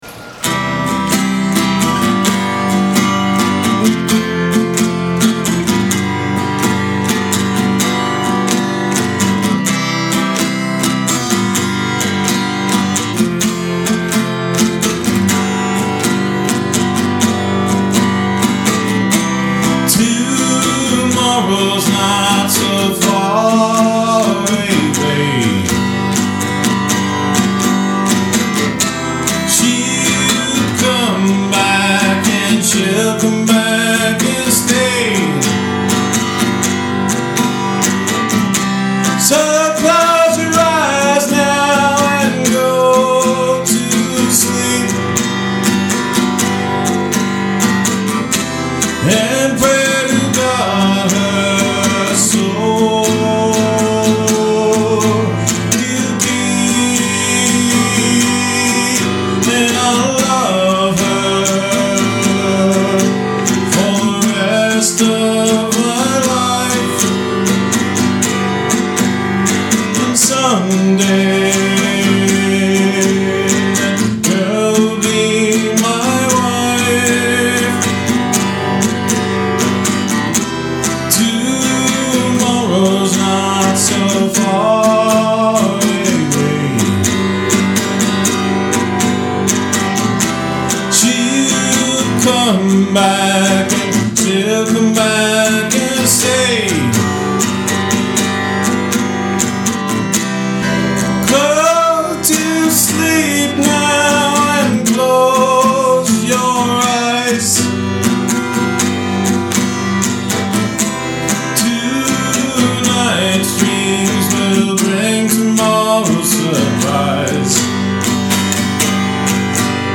I did sing this version also to my fish.